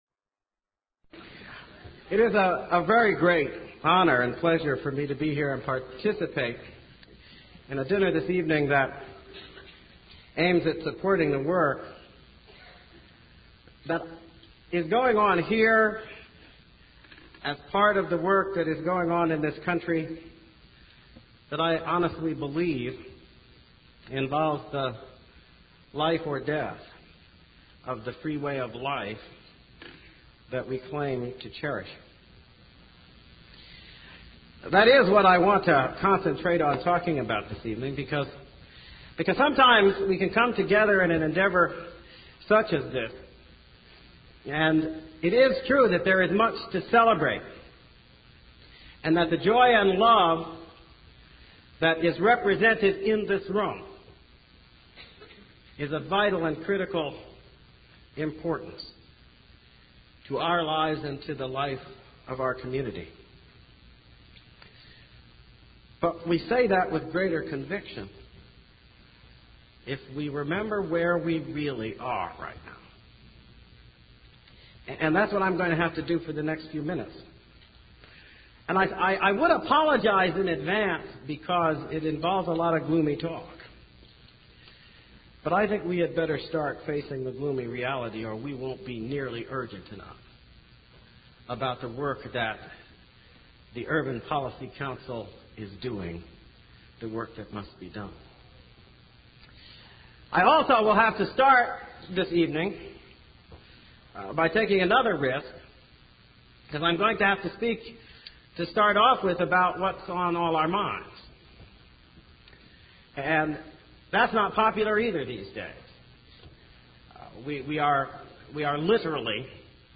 Speech
98_urban.mp3